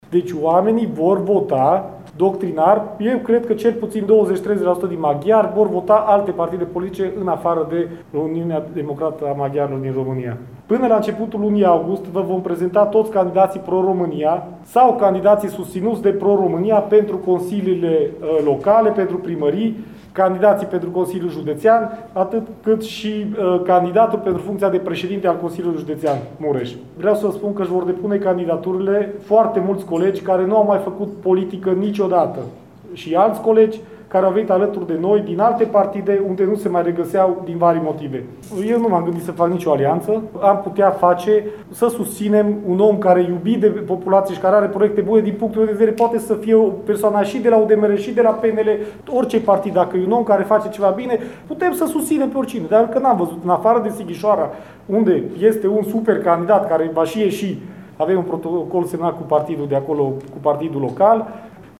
În cadrul conferinței de presă organizate astăzi la Tîrgu Mureș